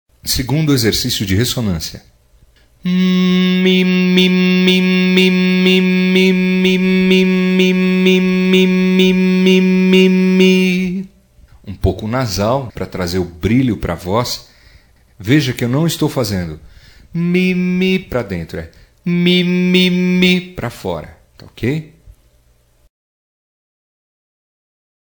05-RESSONANCIA-02_Aquecimento_projecao-da-voz.mp3